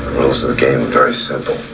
THE VOICE OF LANCE HENRIKSEN